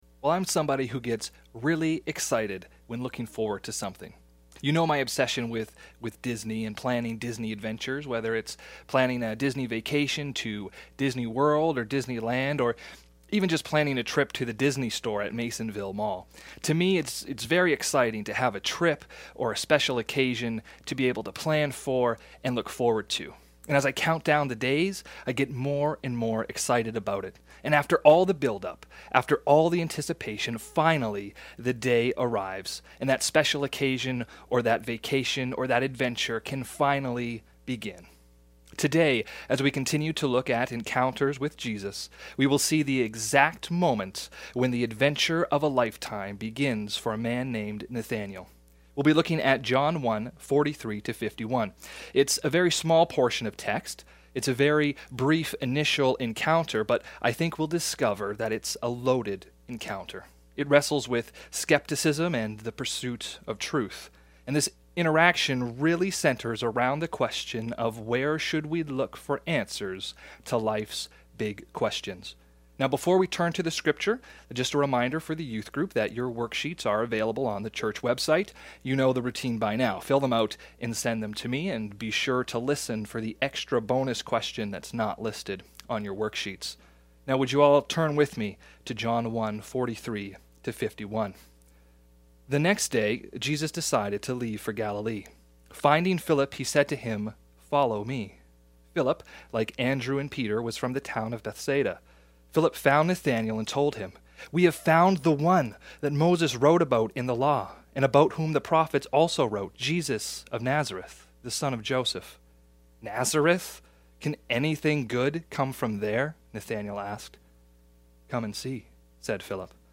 Past Sermons - Byron Community Church